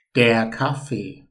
แดร์ คาฟ-เฟ่